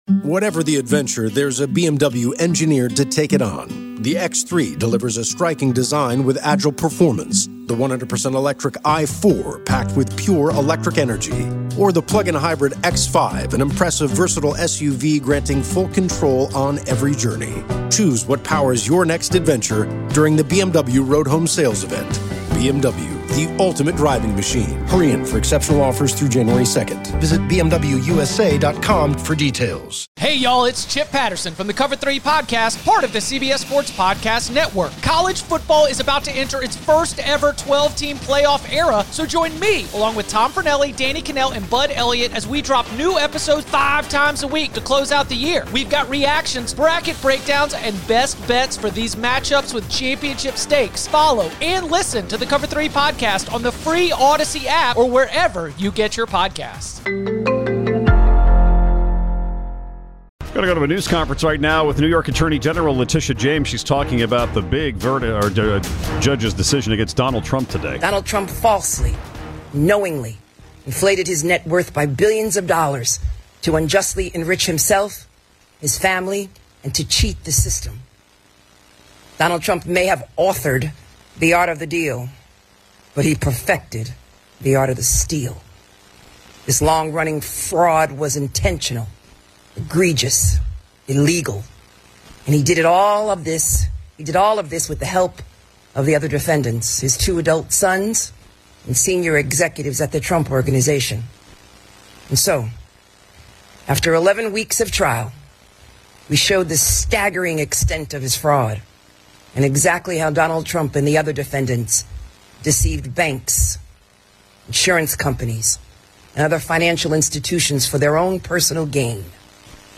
880 Extras - Attorney General Letitia James makes a statement on the ruling in the Donald Trump civil fraud case